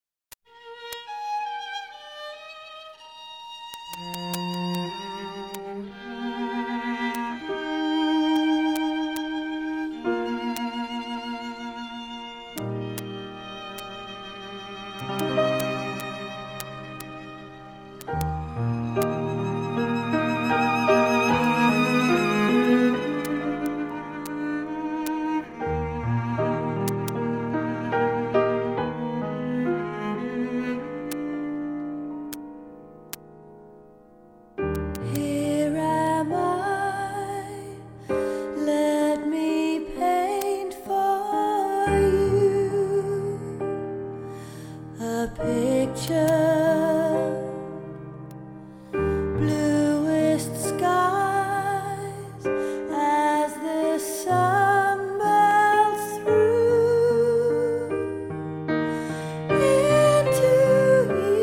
Атмосфера альбома поражает изысканностью.